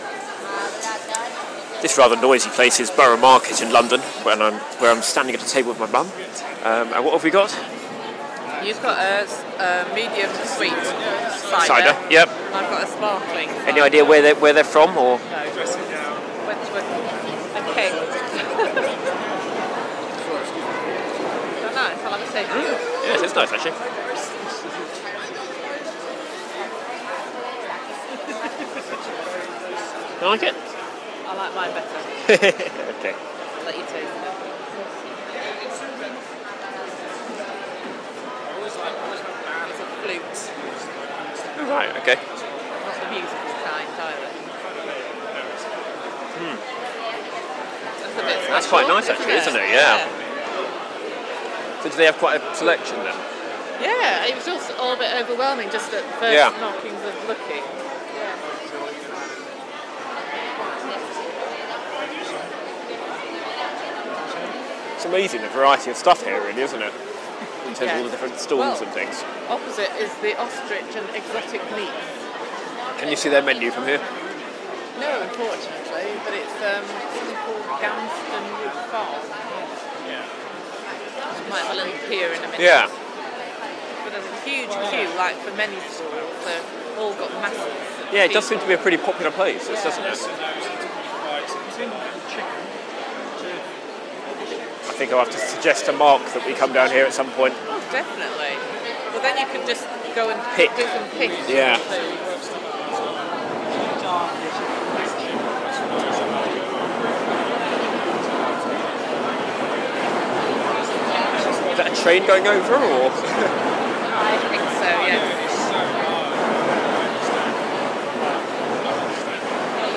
We were lucky to get a table at Roast on Saturday afternoon. This recording contrasts the freneticy of the market hall with sophistication of the dining room.